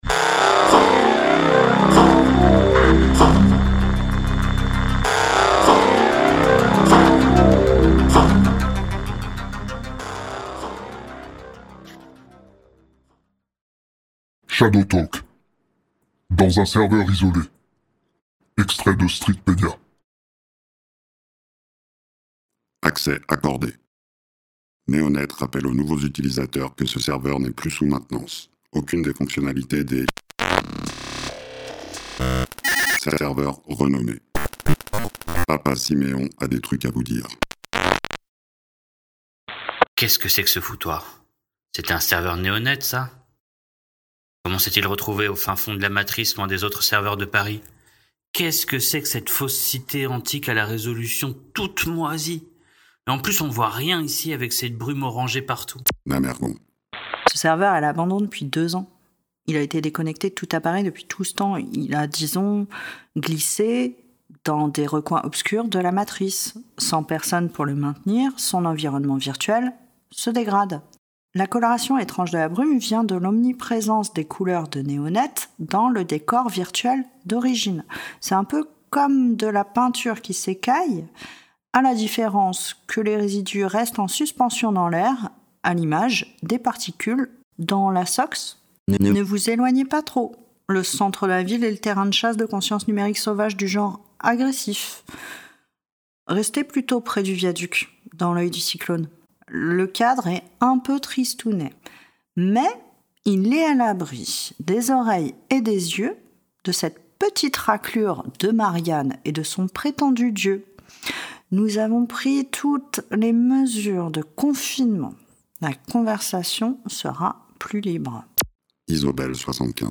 Shadowrunners et révolutionnaires font le point sur l’actualité de l’année 2080… Cet épisode de Shadowrun Stories met en voix un extrait du supplément pour Shadowrun (Anarchy & Shadowrun 6) intitulé Streetpédia.
Pour fêter la sortie du supplément Streetpédia, la plupart des auteurs qui se cachent derrière les Shadowtalkers français ont prêté leur voix à cet épisode, avec pas moins de 12 intervenants pour cet épisode très spécial !